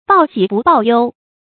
注音：ㄅㄠˋ ㄒㄧˇ ㄅㄨˋ ㄅㄠˋ ㄧㄡ
報喜不報憂的讀法